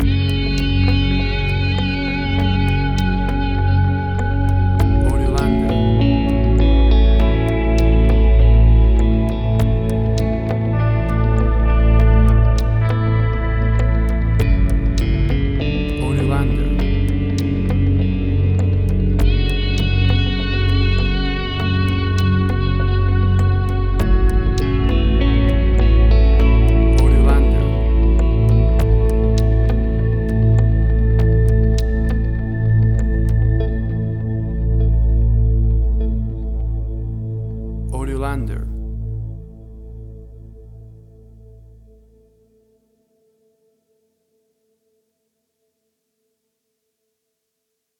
Tempo (BPM): 100